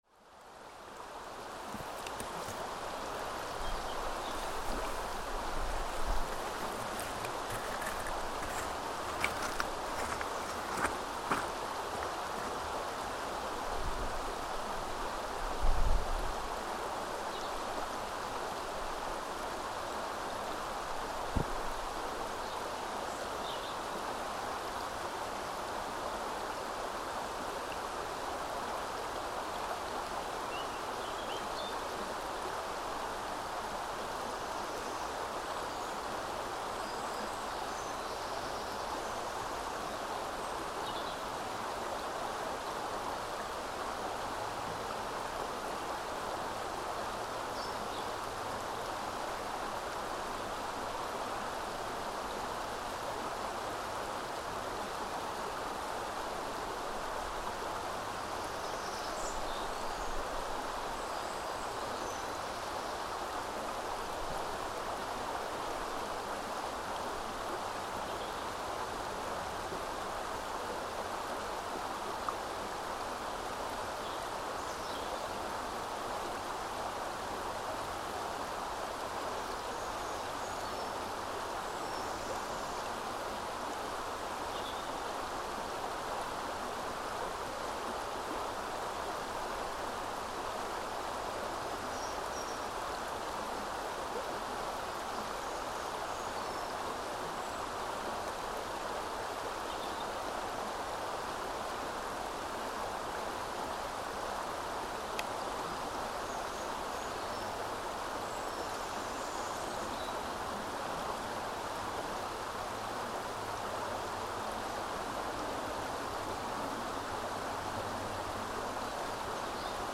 The River Otter gently flows over a bed of stones in Otterton, England, accompanied by the chirping of summer birdsong, and a gentle breeze blowing through the grasses.